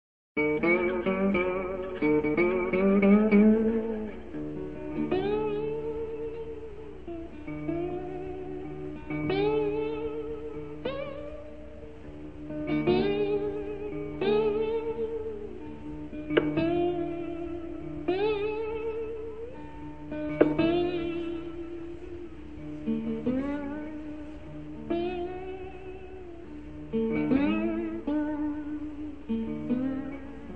Johnson که با Slide اجرا شده توجه کنید.